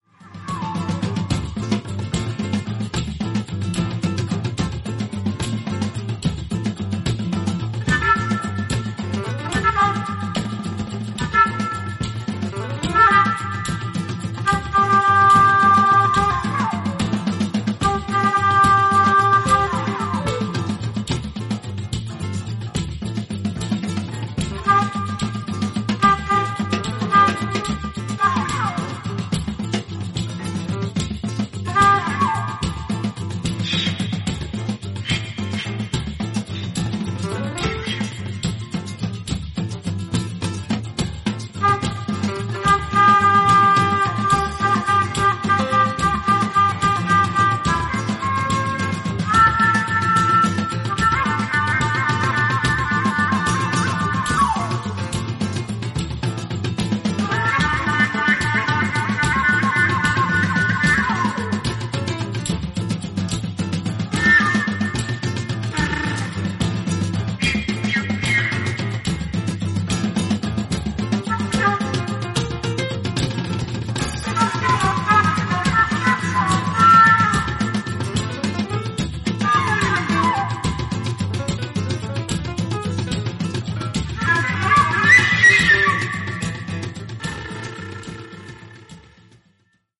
イタリアのライブラリーらしく、メロディが綺麗なボッサやソフトロックも良いですね。